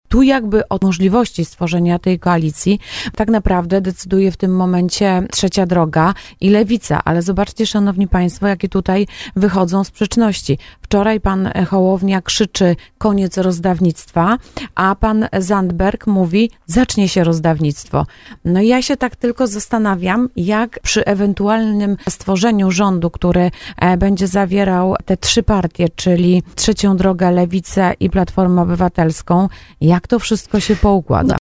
Poseł Anna Pieczarka, która była gościem programu Słowo za Słowo odniosła się także do słów Donalda Tuska, który zaraz po ogłoszeniu wstępnych sondażowych wyników wyborów ogłosił koniec rządów PiS.